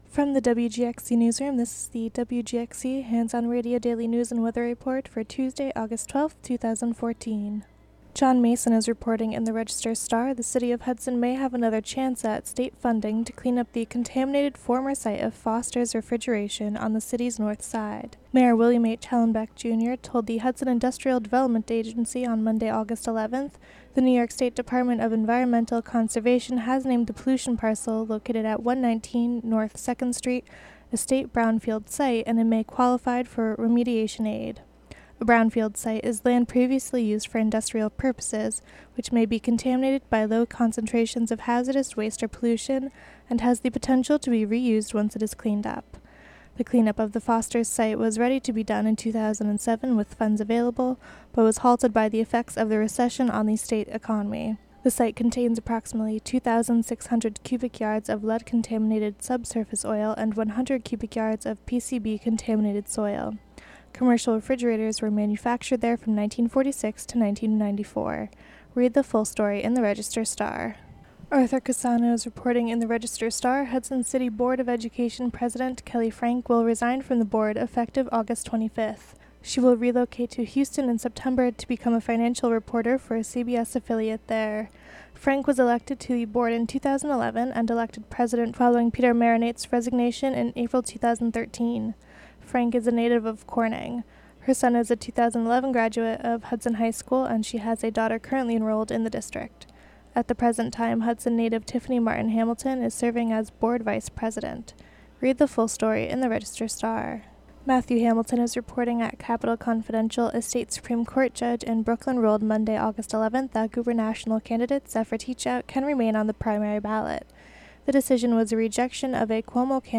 Local news and weather for Tuesday, August 12, 2014.